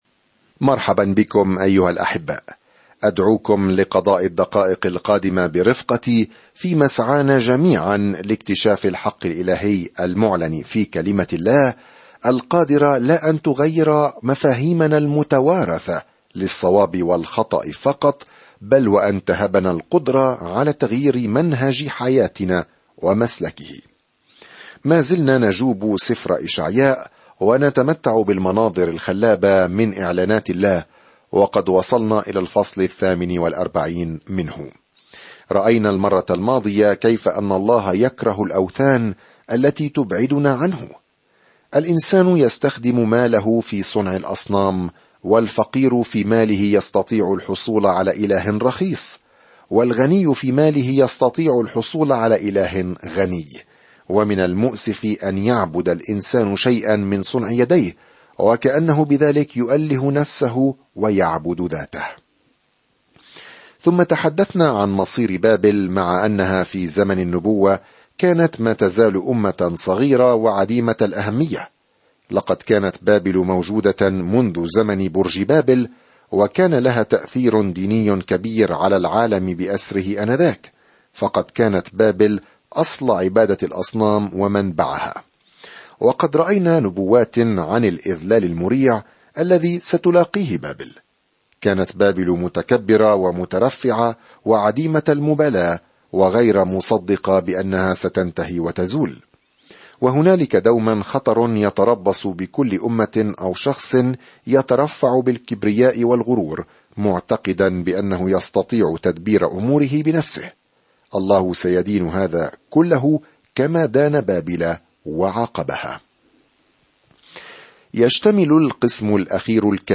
الكلمة إِشَعْيَاءَ 48 إِشَعْيَاءَ 49 إِشَعْيَاءَ 1:50 يوم 35 ابدأ هذه الخطة يوم 37 عن هذه الخطة ويصف إشعياء، المسمى "الإنجيل الخامس"، ملكًا وخادمًا قادمًا "سيحمل خطايا كثيرين" في وقت مظلم عندما يسيطر الأعداء السياسيون على يهوذا. سافر يوميًا عبر إشعياء وأنت تستمع إلى الدراسة الصوتية وتقرأ آيات مختارة من كلمة الله.